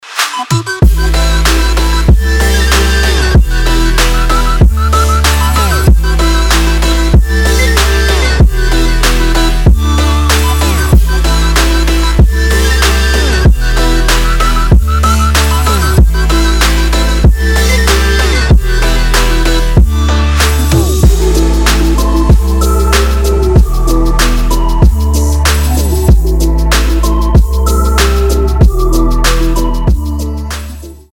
remix
Electronic
без слов
Trap
Bass
Стиль: trap